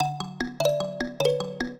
mbira
minuet4-8.wav